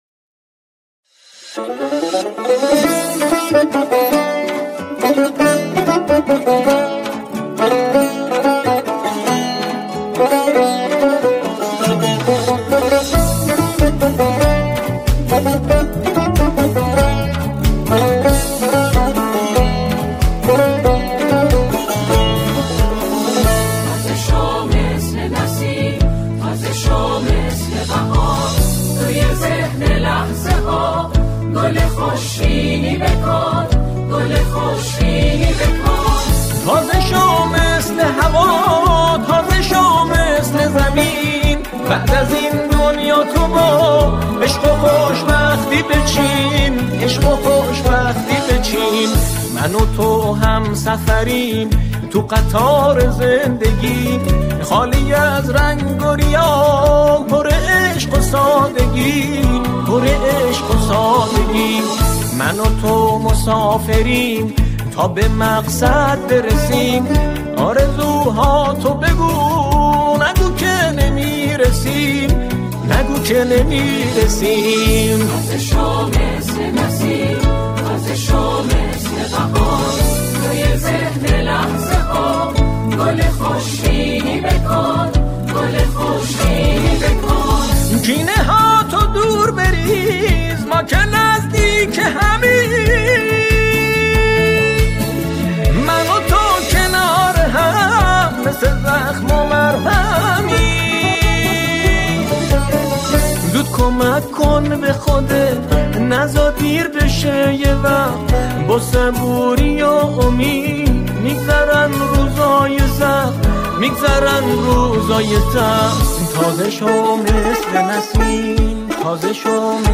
سرودهای انگیزشی